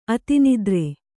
♪ ati nidre